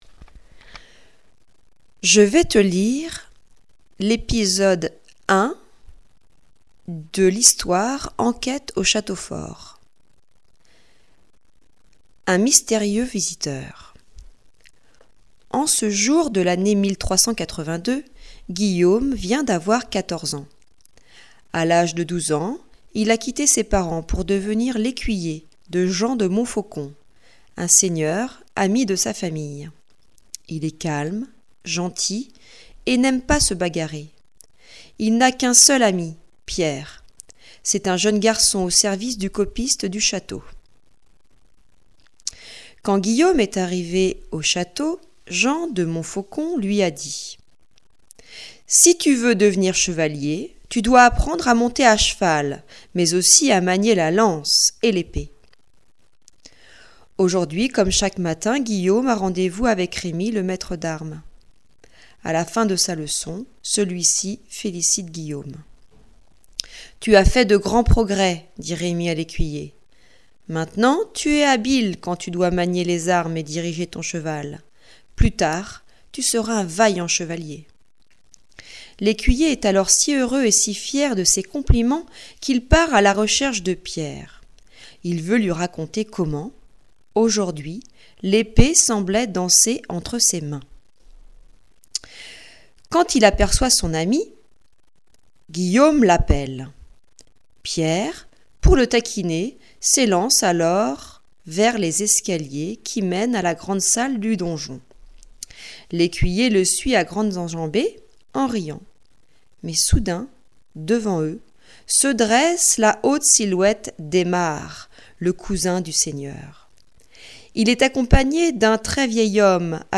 Lecture de l'histoire Enquête au château : un mystérieux chevalier